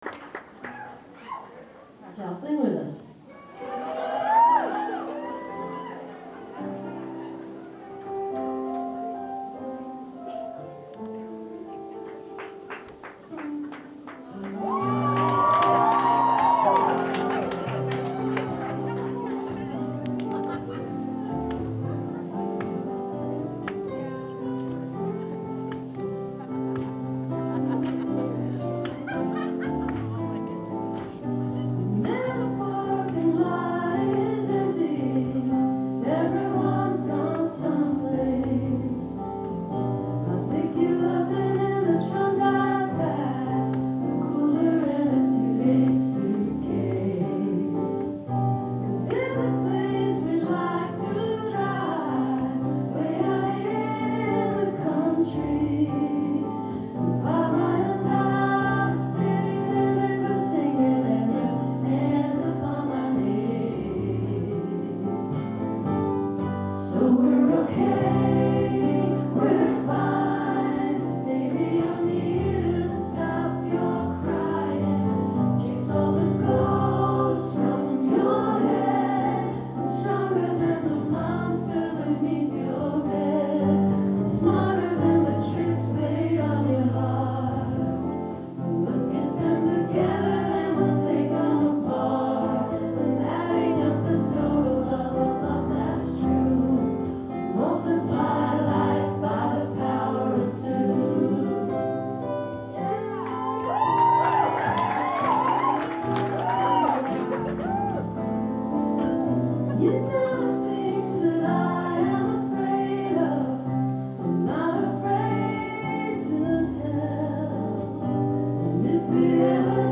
birchmere music hall - alexandria, virginia